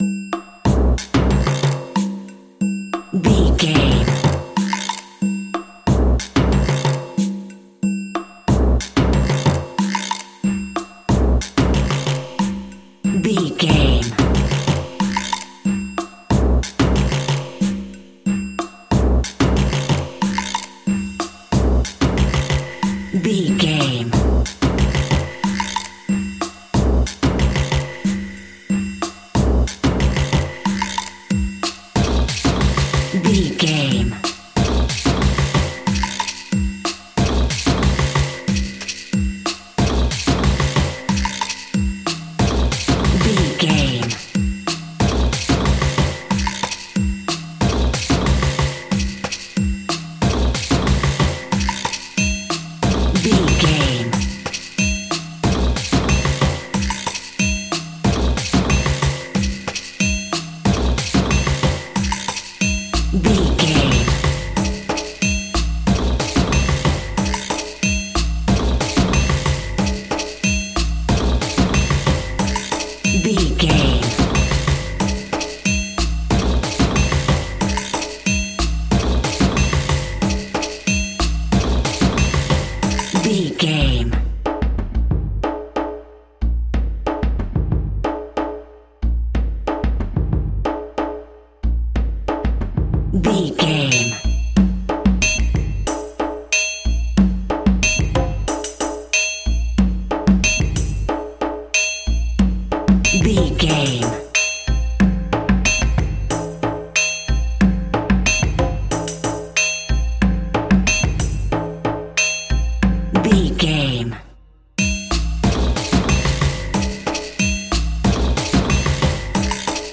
Tribal Drumming.
Atonal
scary
ominous
dark
haunting
eerie
drums
percussion
synth
pads